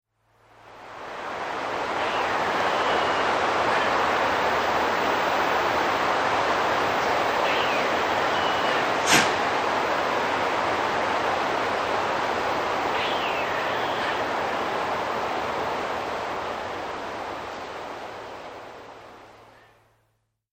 Звук лающей лани